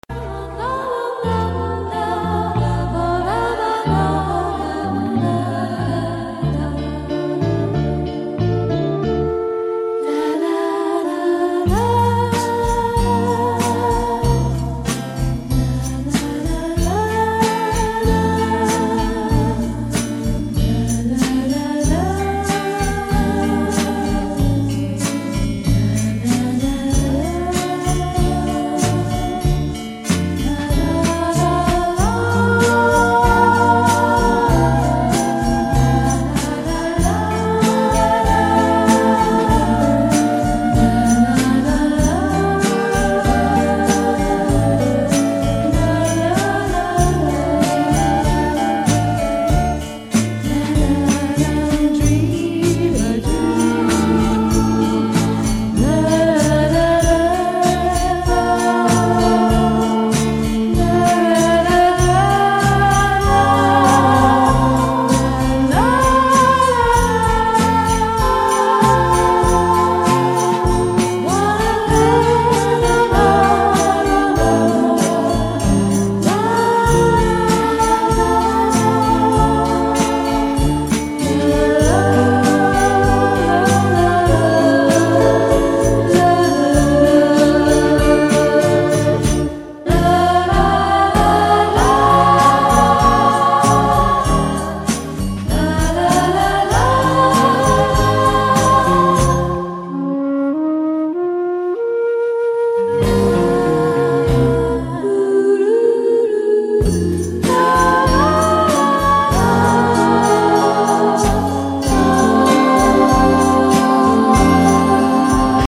от бельгийского женского трио
Но отгрызано начало и конец.
Жанр: Psychedelic Pop/Easy Listening
vocals